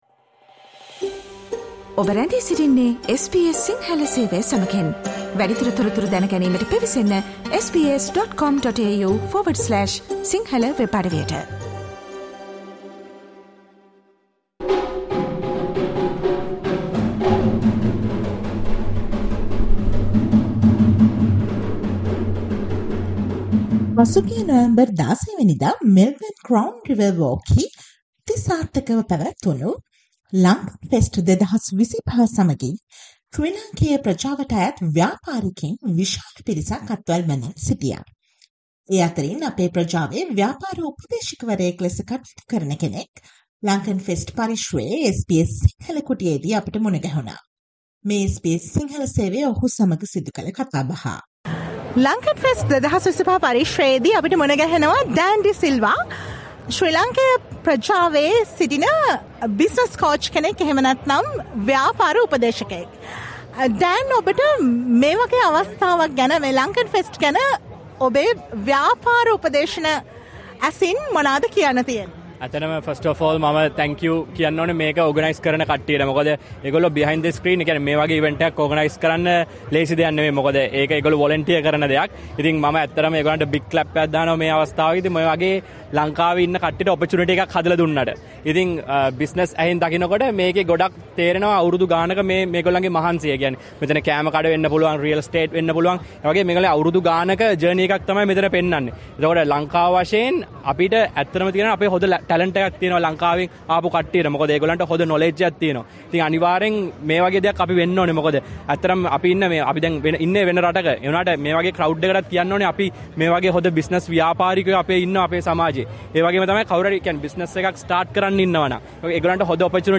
speaking with vendors and participants at Lankan Fest 2025, held at Crown Riverwalk in Melbourne on 16 November.